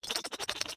rabbit-v2.ogg